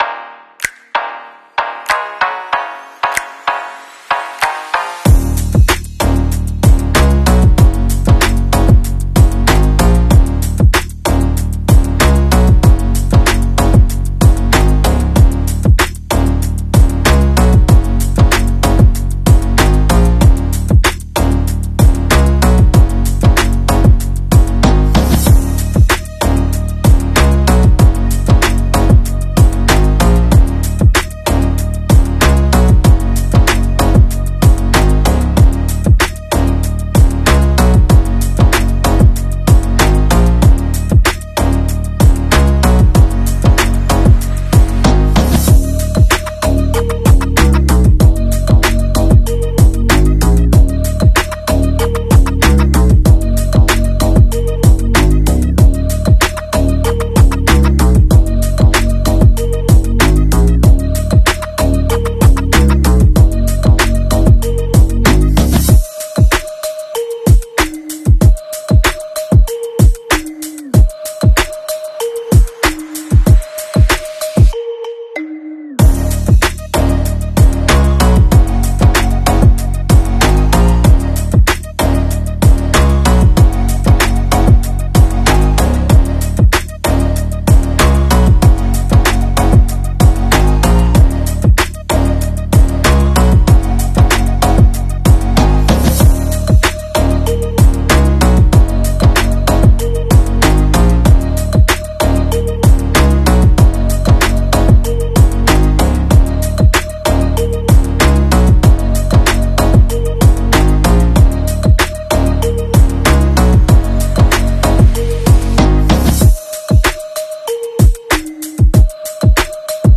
Demonstrating his ability to stay to task commanded. I’m kicking the retrieve item around while on a bite showing he will not come off the grip until I command him to “Fetch” the item.